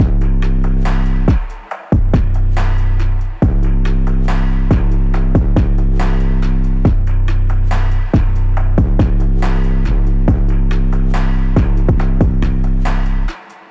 Dirty Phonk Saturated Drum Loop F#M/Gm 158.ogg
Hard punchy kick sample for Memphis Phonk/ Hip Hop and Trap like sound.